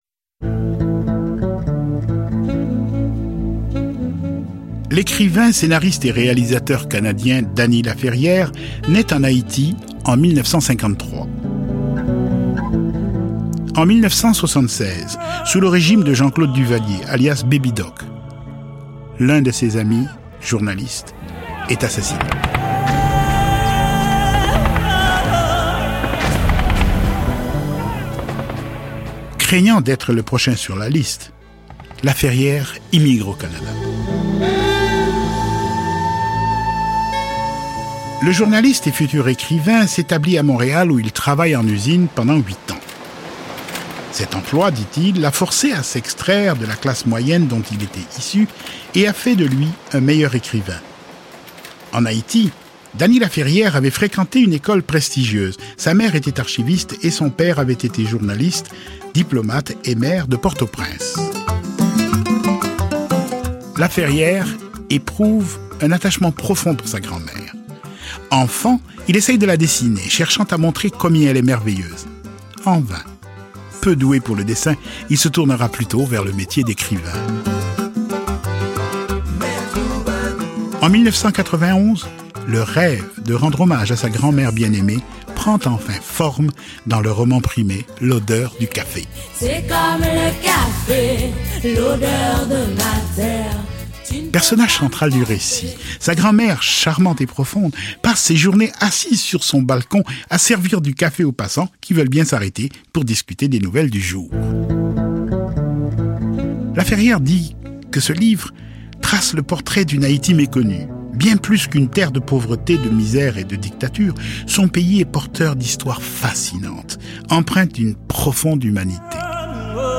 Narrateur: